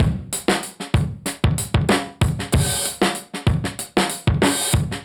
Index of /musicradar/dusty-funk-samples/Beats/95bpm/Alt Sound